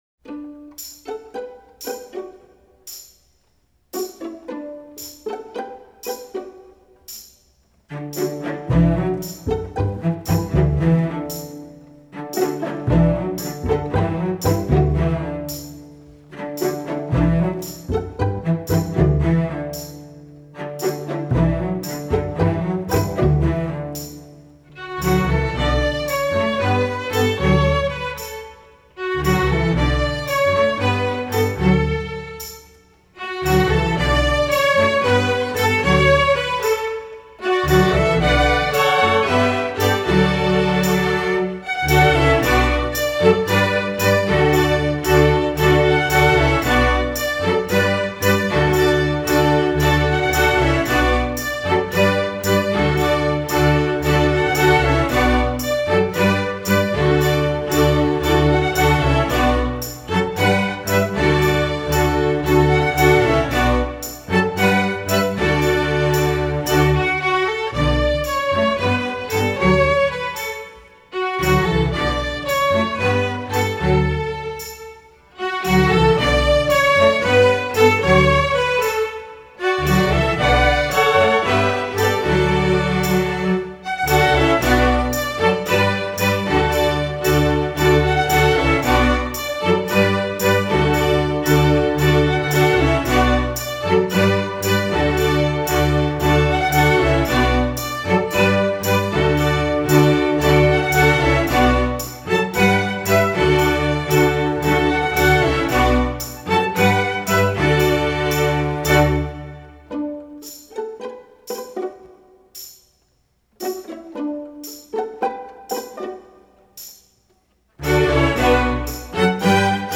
Instrumentation: string orchestra
pop